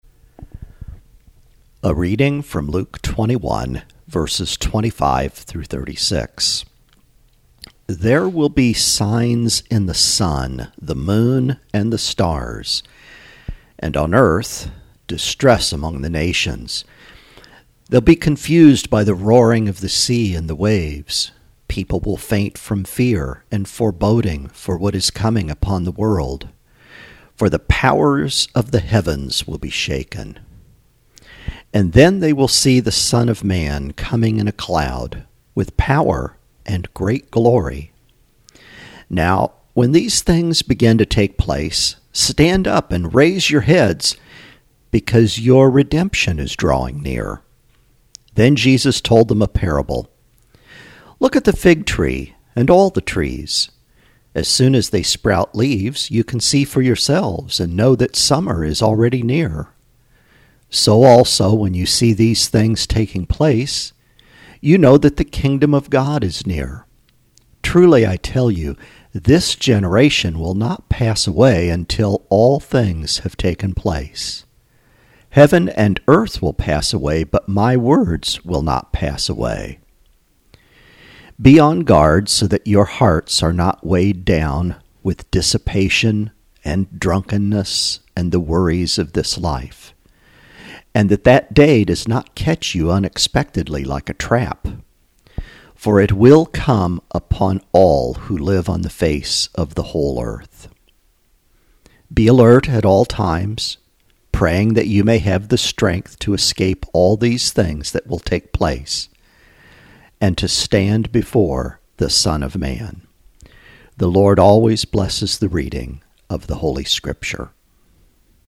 Reading: Luke 21: 25-36